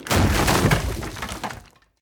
destroy4.ogg